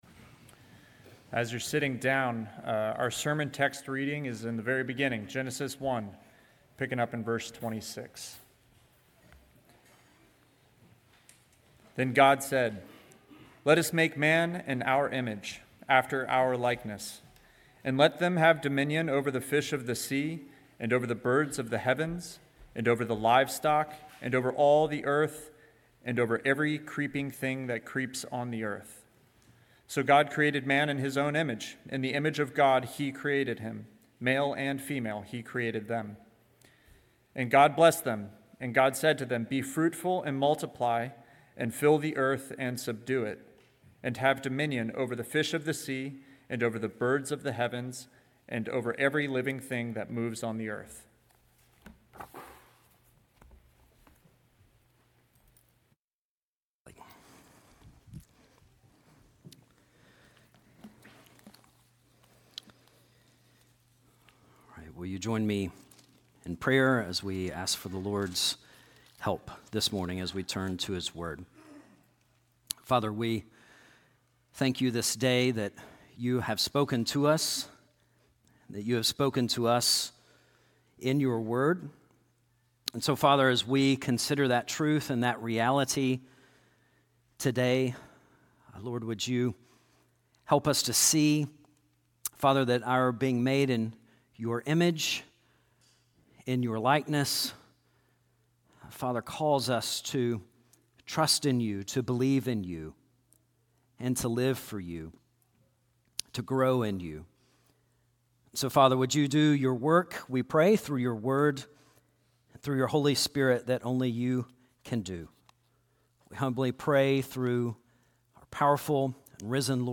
sermon8.3.25.mp3